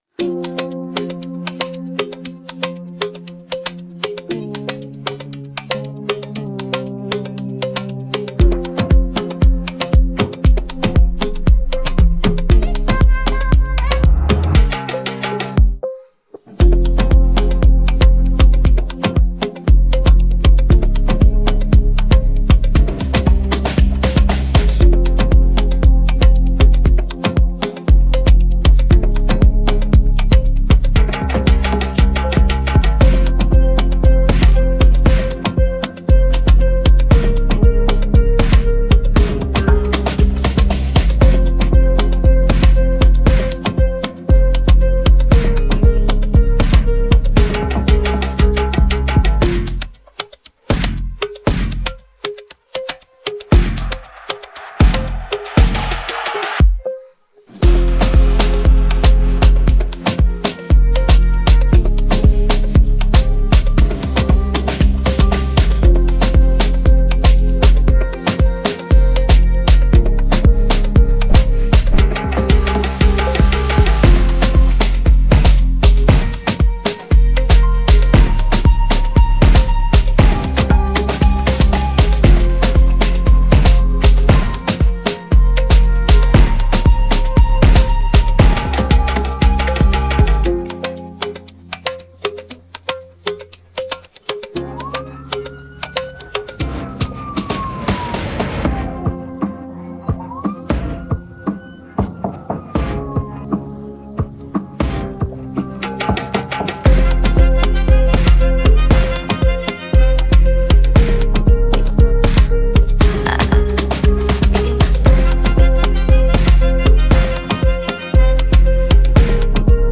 Afro pop Afrobeats Pop